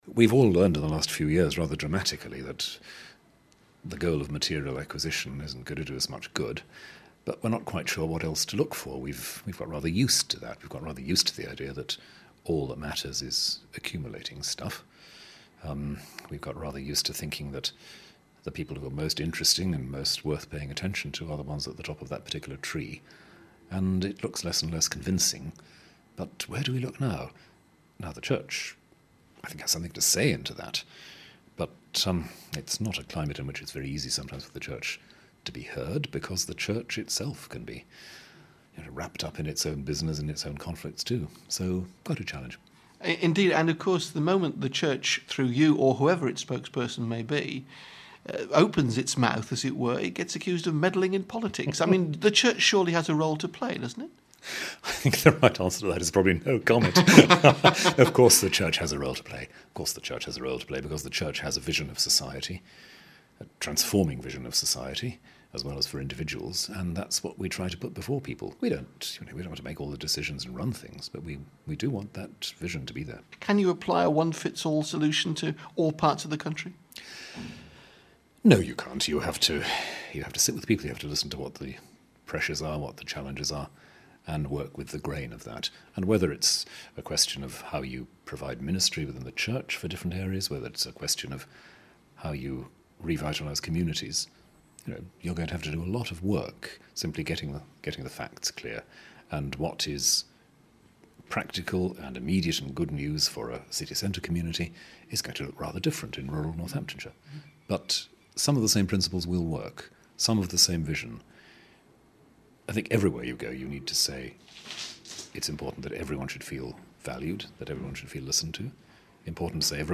speaks to Rowan Williams the current Archbishop of Canterbury for BBC Radio Northampton